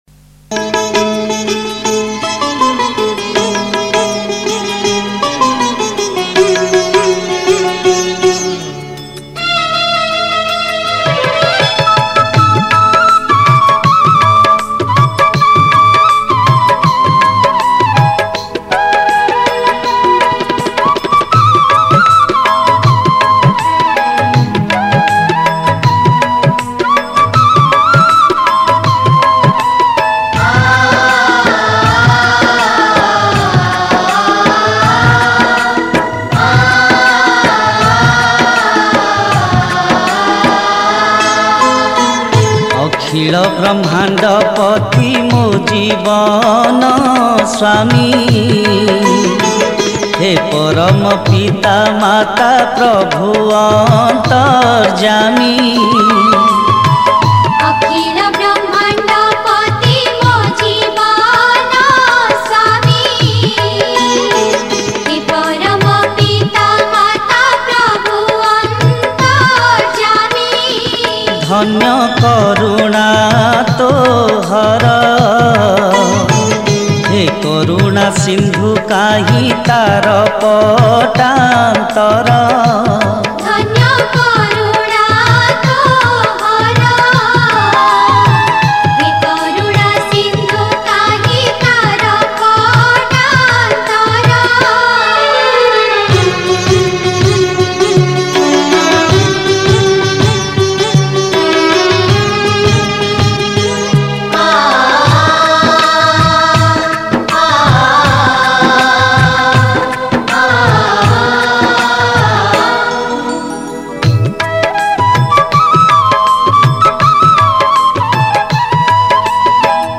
Category: Prathana